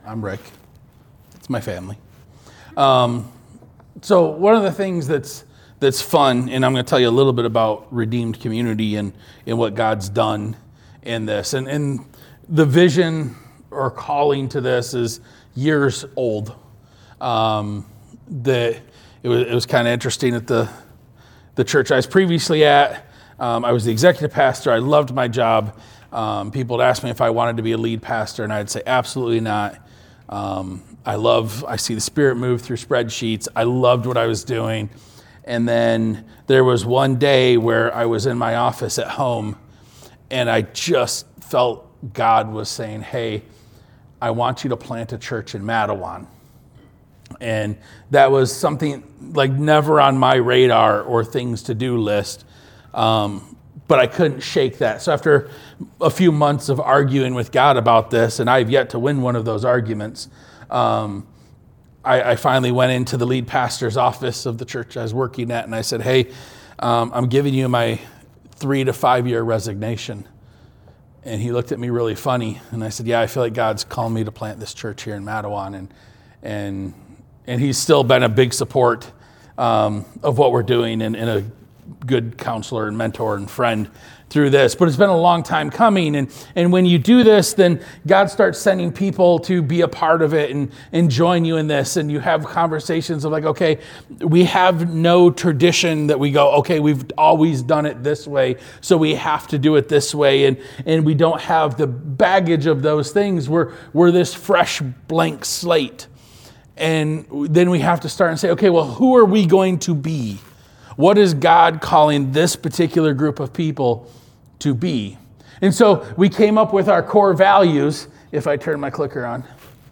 Sermon 12-14.mp3